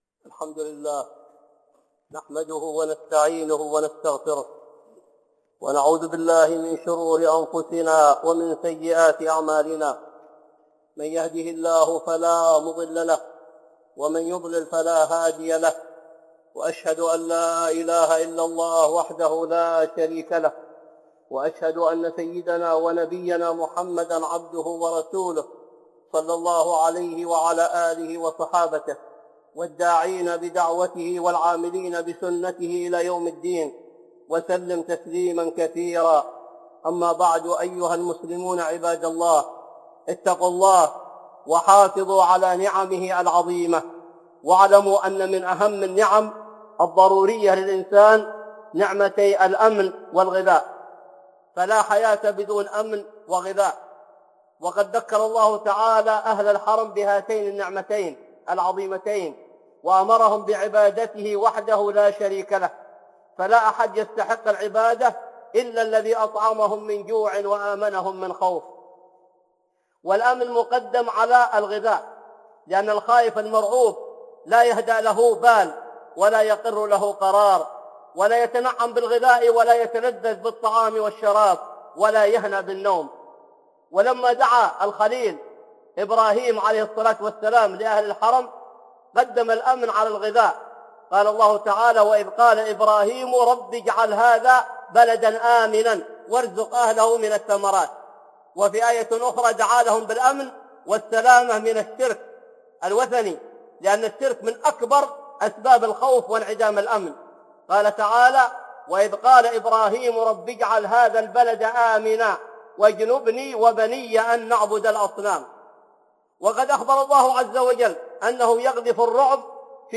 (خطبة جمعة) الأمن والغذاء